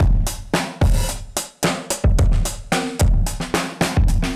Index of /musicradar/dusty-funk-samples/Beats/110bpm/Alt Sound
DF_BeatB[dustier]_110-03.wav